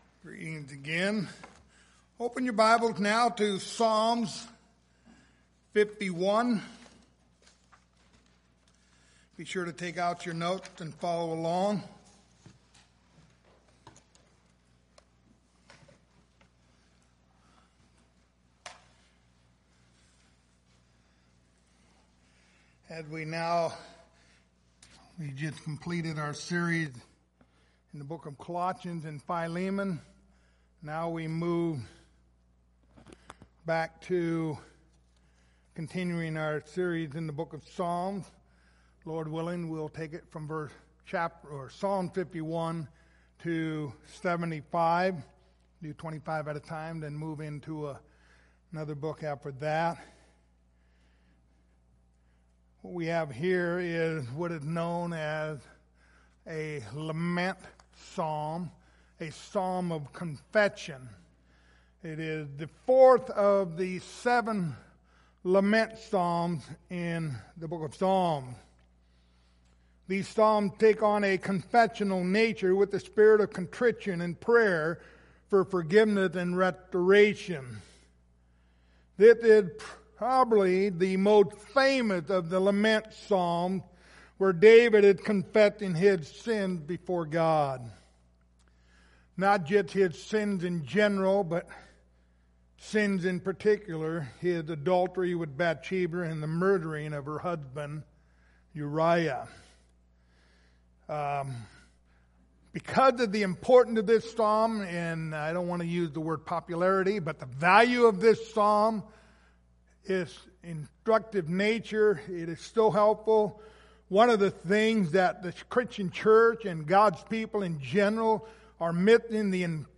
Passage: Psalms 51:1-6 Service Type: Sunday Morning Topics